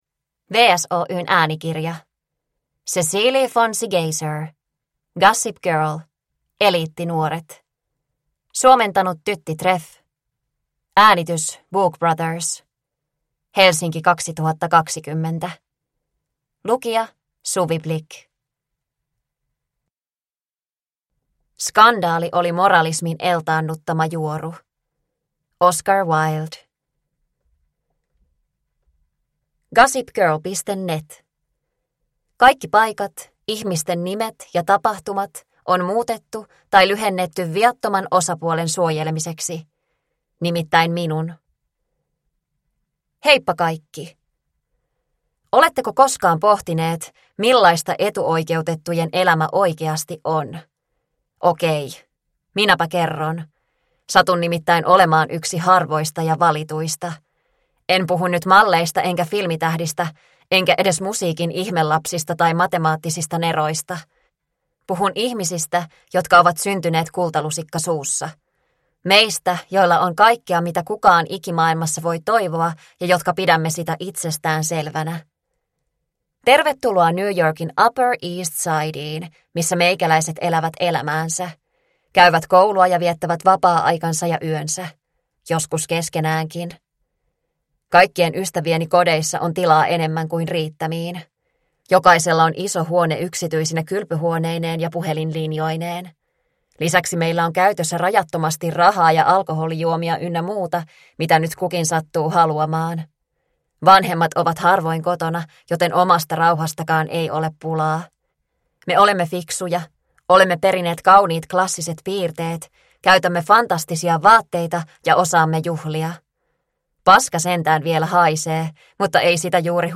Gossip Girl - Eliittinuoret – Ljudbok